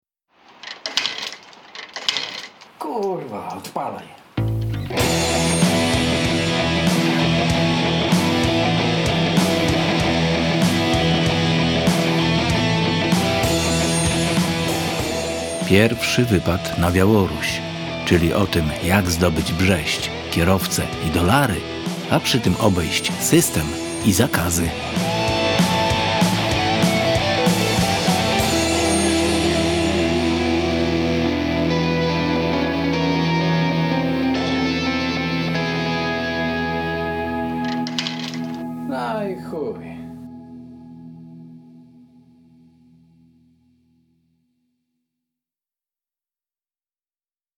Autorski audiobook (osobiście napisany, czytany i zrealizowany) z cyklu motoopowieści – motocyklowych podróży po byłych krajach demoludów – z namiotem, na dziko, wzdłuż granic… wszelakich.